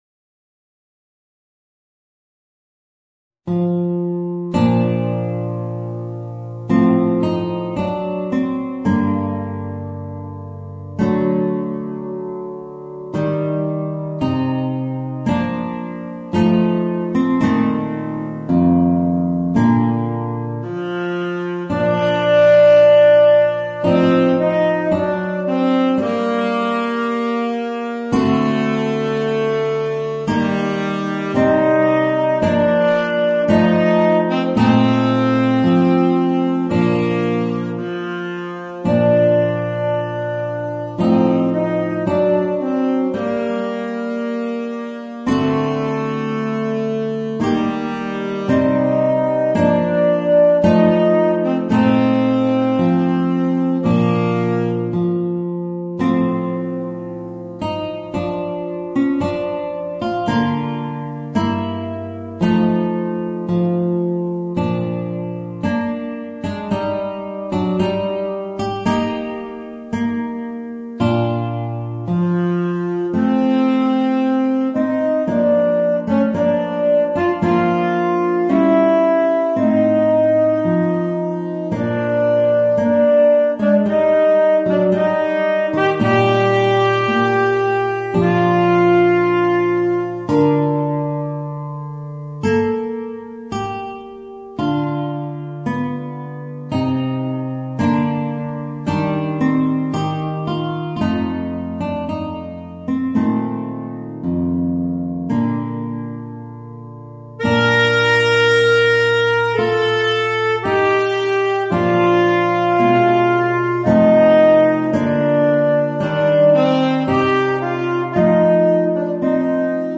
Voicing: Tenor Saxophone and Guitar